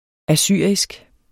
assyrisk adjektiv Bøjning -, -e Udtale [ aˈsyˀʁisg ] Betydninger 1. fra oldtidsriget Assyrien; vedr.